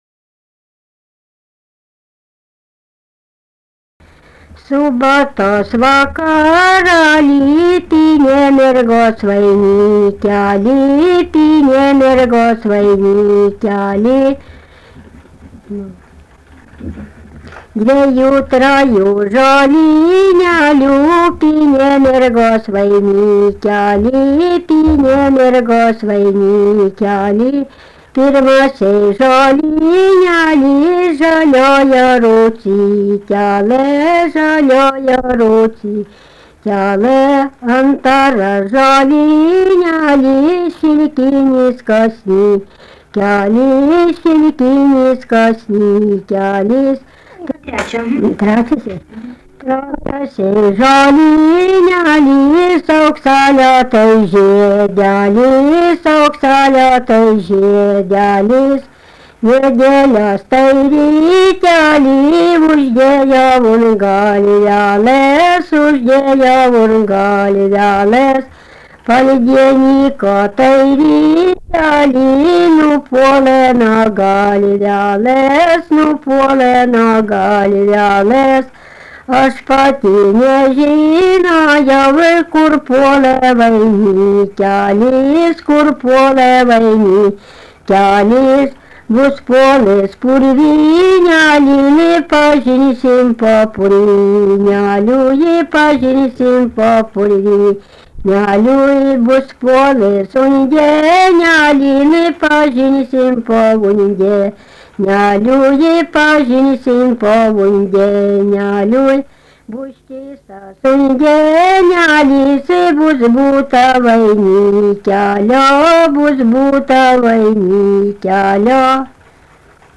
Dalykas, tema daina
Erdvinė aprėptis Jakeliai
Atlikimo pubūdis vokalinis